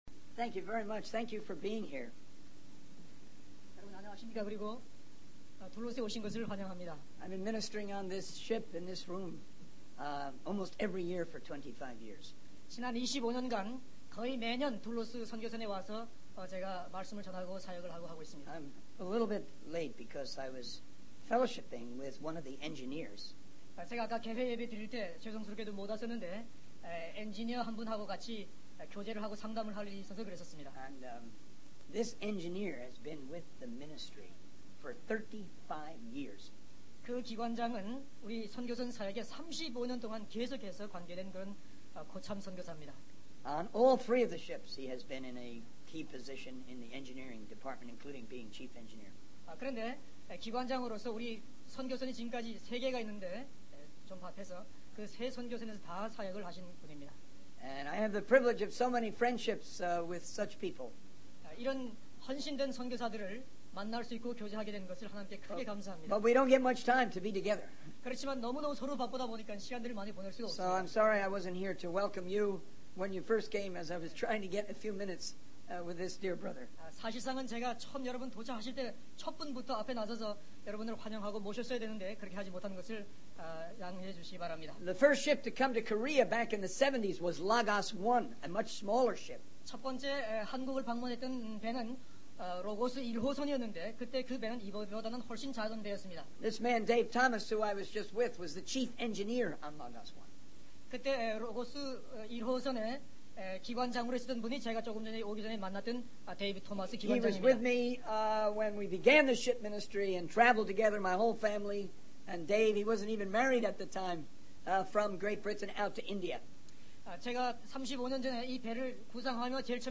Missions Conf 16.7.2001 Eng to Korean
In this sermon, the speaker shares his deep love for the book of Proverbs and how it has been a source of wisdom and inspiration for him for over 44 years.